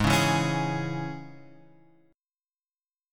G# Suspended 4th Sharp 5th